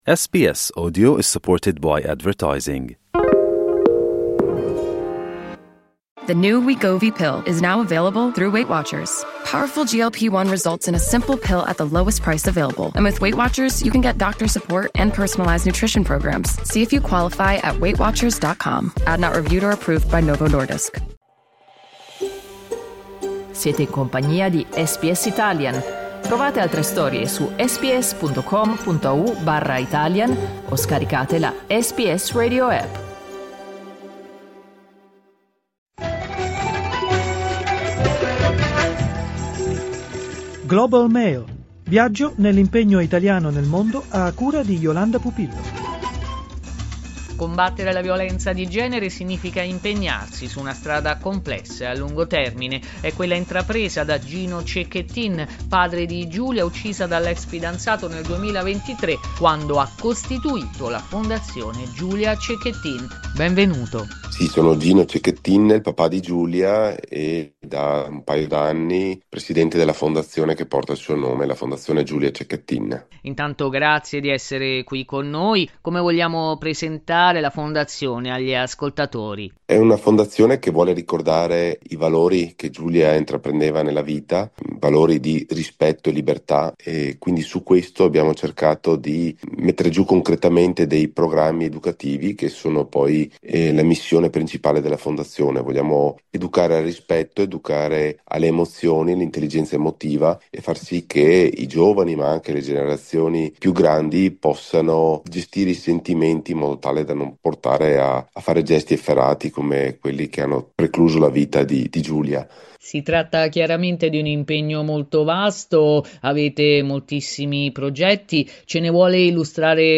Lo stesso Gino Cecchettin ha spiegato qual è la missione della fondazione.
Clicca sul tasto "play" in alto per ascoltare l'intervista integrale